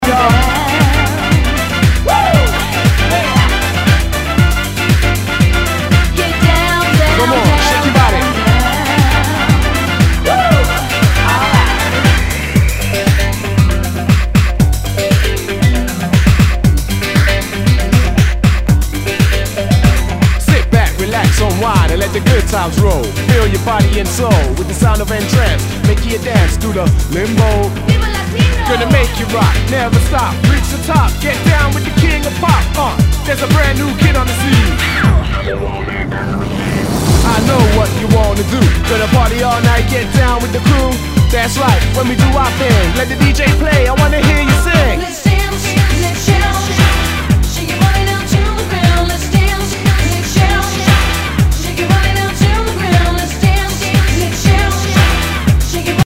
HOUSE/TECHNO/ELECTRO
ナイス！ユーロ・ヴォーカル・ハウス！